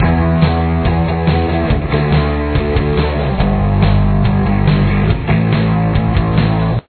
Both guitar and bass: